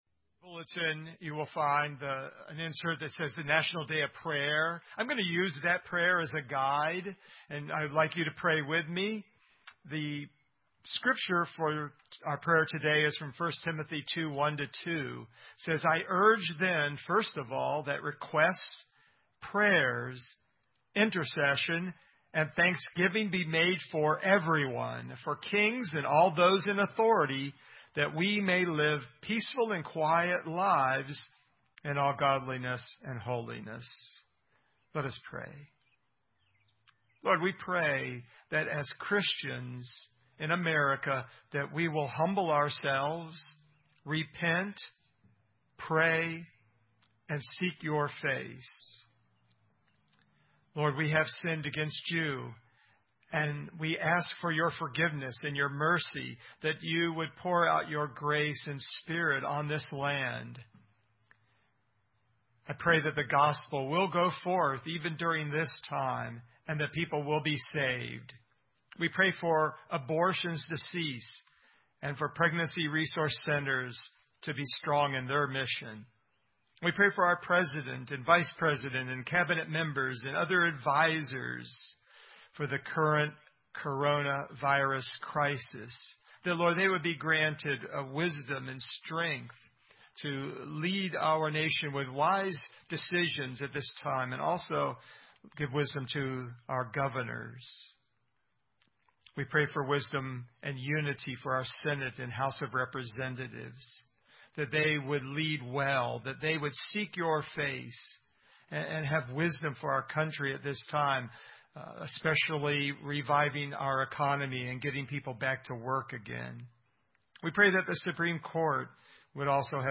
The first sermon in the Fruit of the Spirit Sermon Series is on love, Keeping Love Alive. We will learn what love is and how it acts, for love is a verb & verbs are action words.
Service Type: Sunday Morning